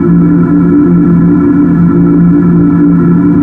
AmbDroneD.wav